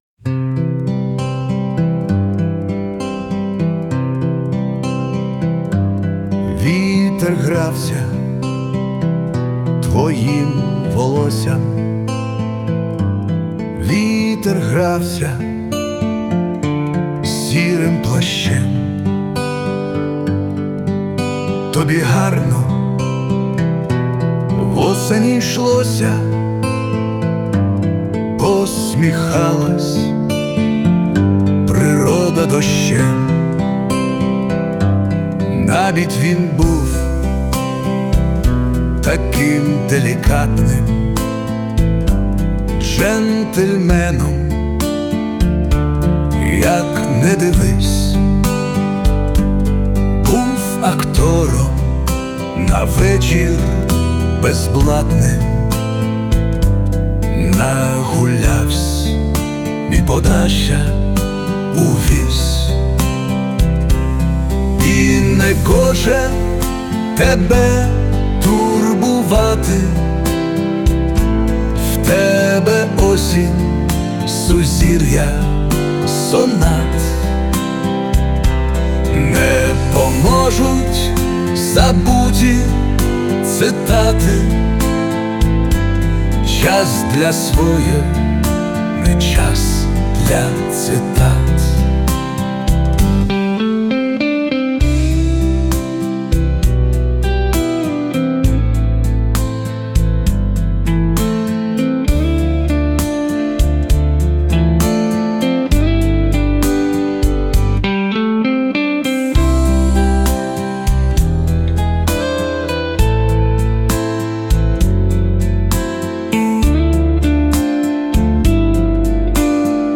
Кавер
Дуже гарна пісня, Друже! 16
та й гітара вже перестала кусатися hi
кавер означає, що я завантажую свій варіант, а програма його вдосконалює ...